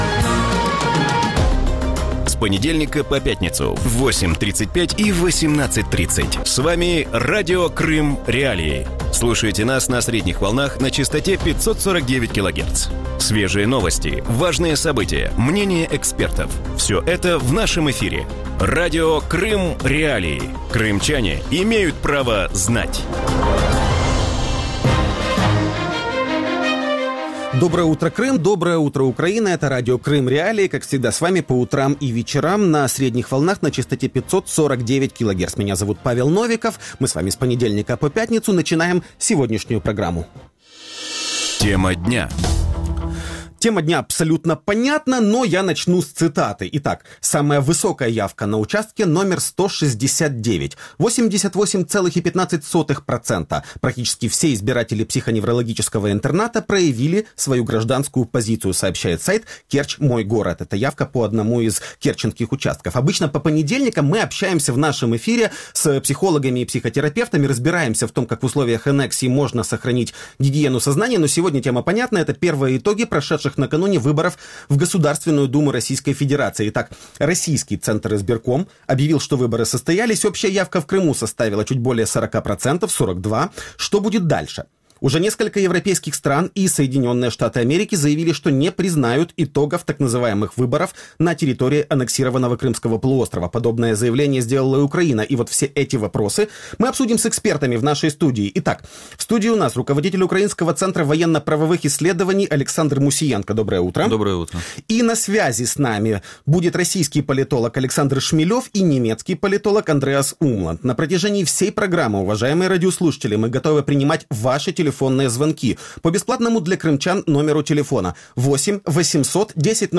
Вранці в ефірі Радіо Крим.Реалії говорять про вибори в російську Державну Думу, які відбулися і на території анексованого Криму. Якими є їхні попередні підсумки? Як пройшло голосування?